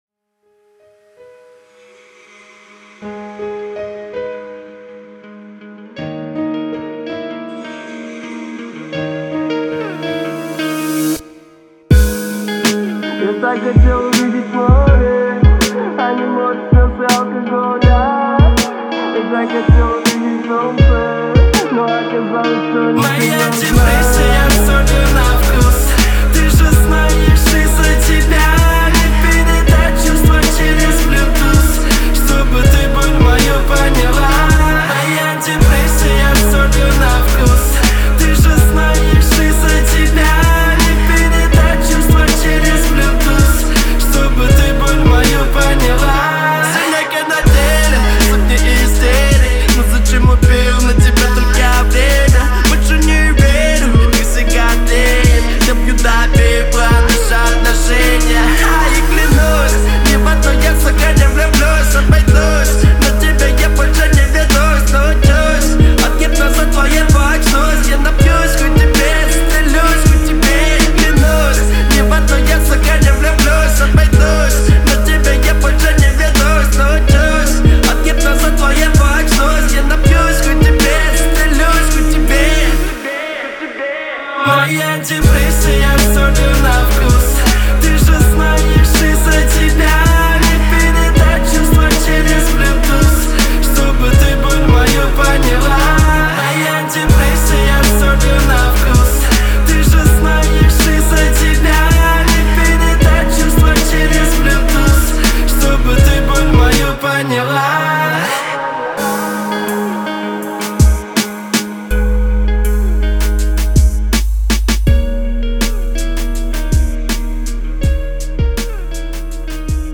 сочетая ритмичные фразы с мелодичными припевами.